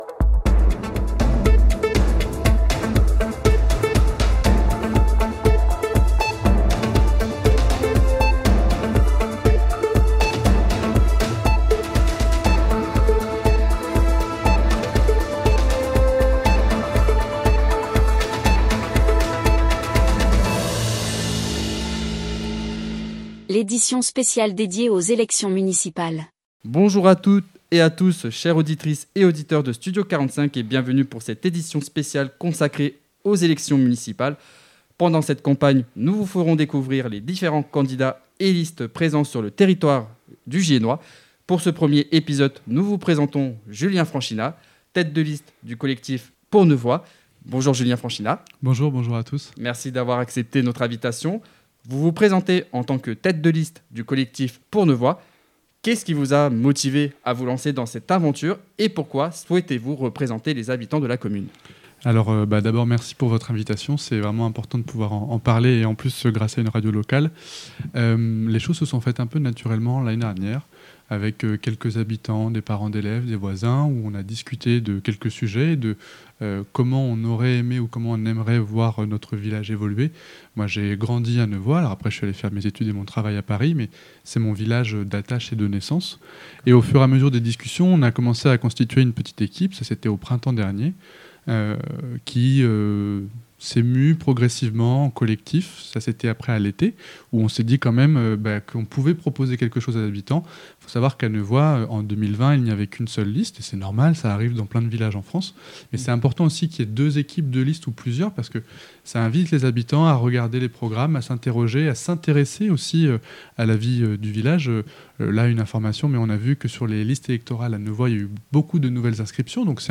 Interview spéciale